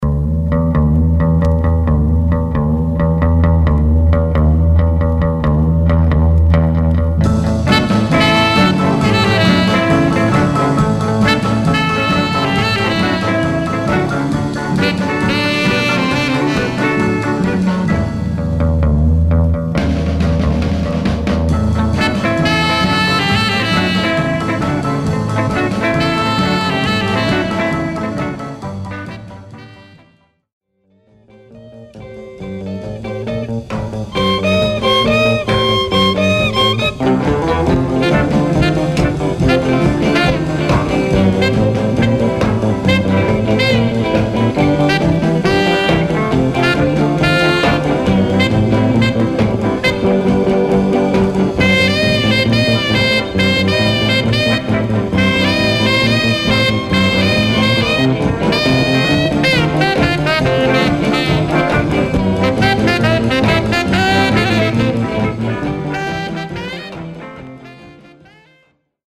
Some surface noise/wear Stereo/mono Mono
R & R Instrumental